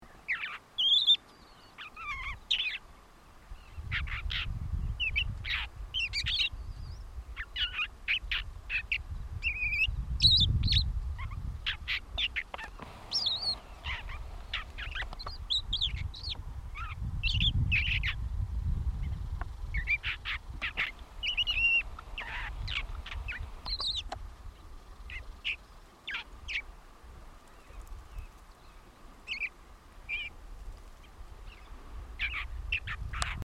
Mimus patagonicus
Nome em Inglês: Patagonian Mockingbird
Fase da vida: Adulto
Localidade ou área protegida: Área Natural Protegida Península Valdés
Condição: Selvagem
Certeza: Observado, Gravado Vocal
Calandria-Mora.mp3